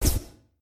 whiz_sub_01.ogg